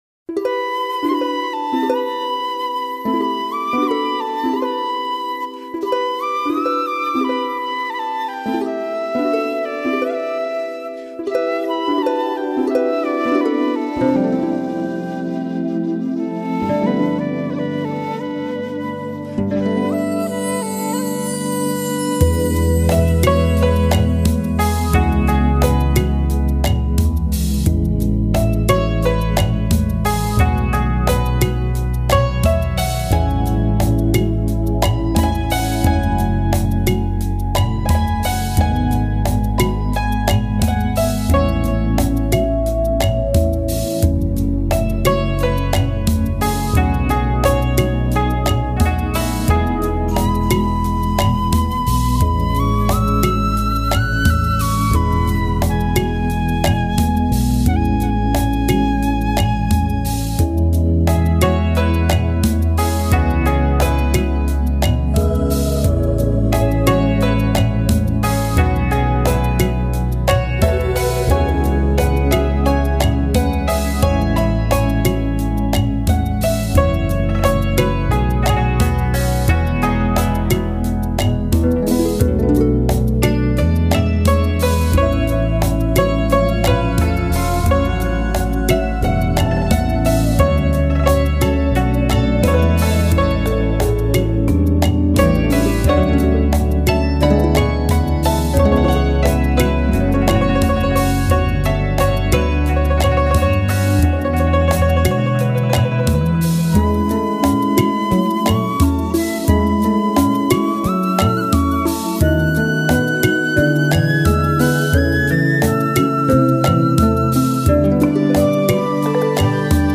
这一次，更成为力量的突发来源，同时，人类的声音加入，让专辑的旅行的色彩感觉更浓烈。
夕阳，伤感和对明天的希望。